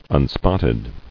[un·spot·ted]